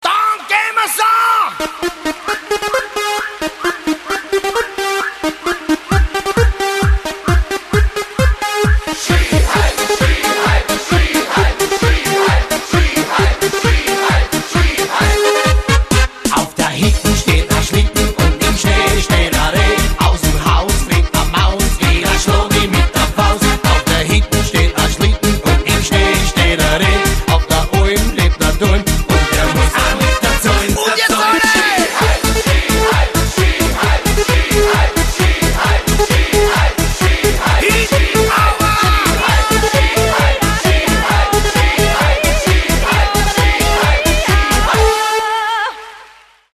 AUS DEM APRES-SKI BEREICH . . .